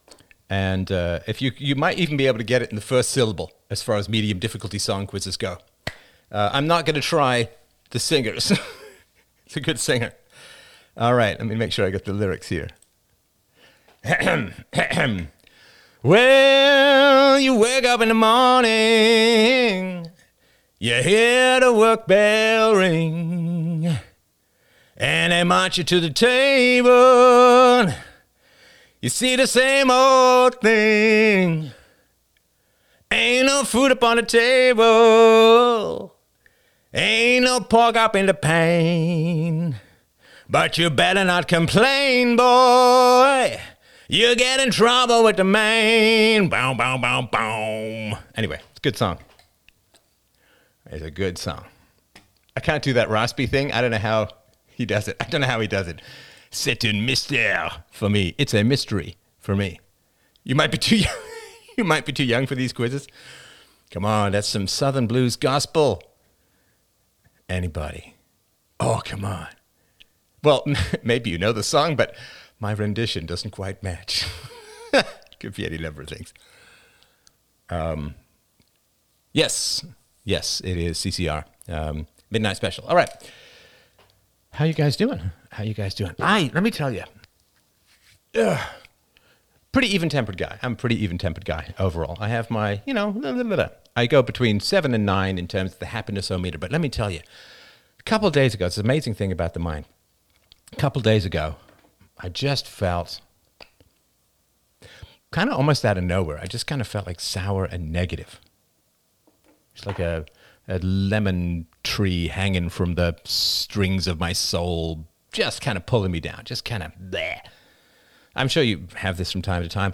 Freedomain Livestream 13 Apr 2023!